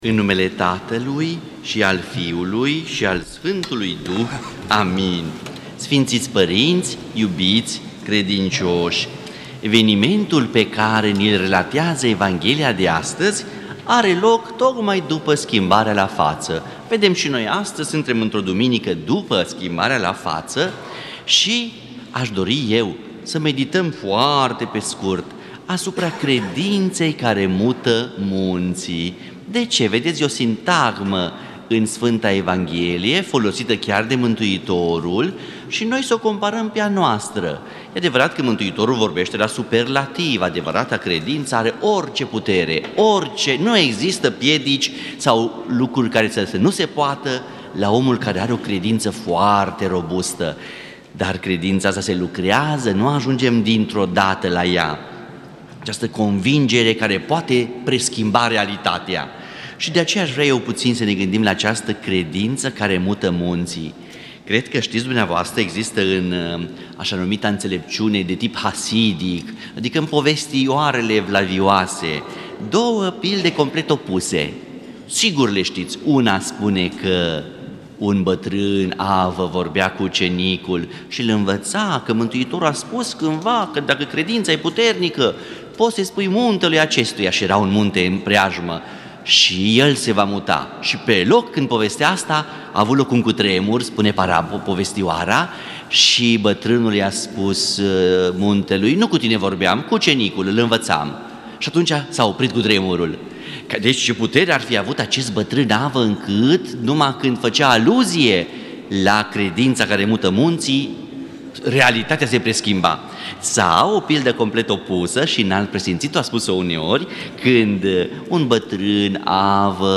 Credința care mută și munții - Predică la Duminica a X-a după Rusalii (vindecarea lunatecului)